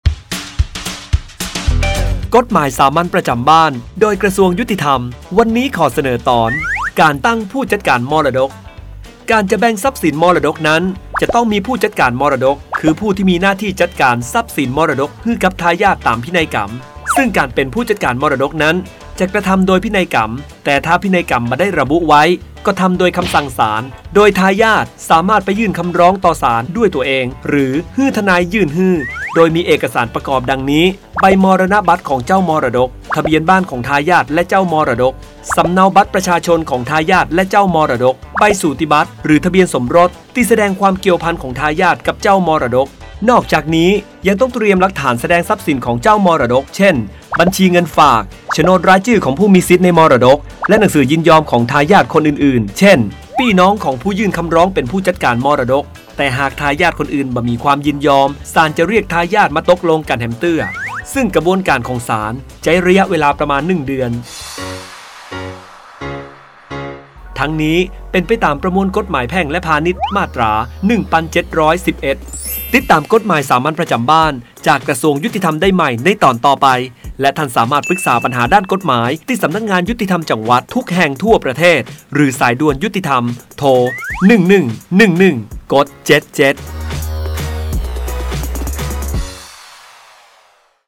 กฎหมายสามัญประจำบ้าน ฉบับภาษาท้องถิ่น ภาคเหนือ ตอนการตั้งผู้จัดการมรดก
ลักษณะของสื่อ :   คลิปเสียง, บรรยาย